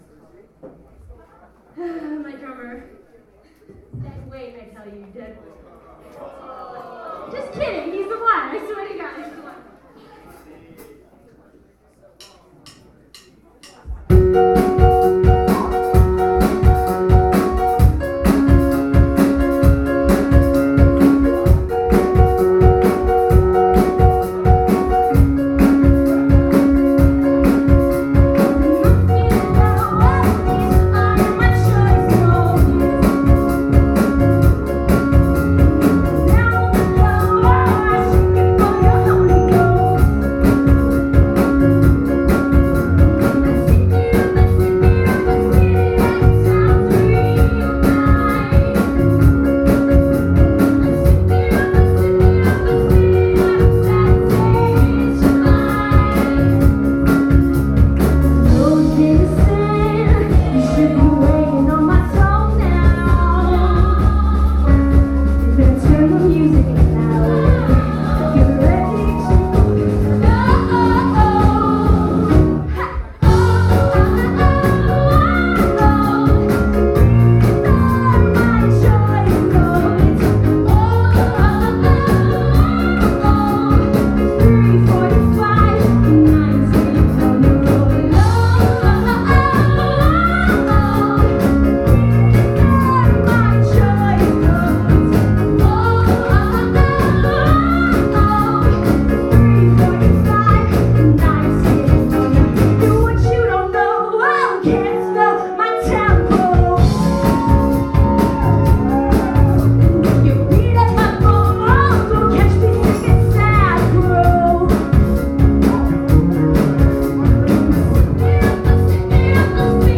soul-style female backing vocals
three dedicated vocalists
Public Assembly CMJ performances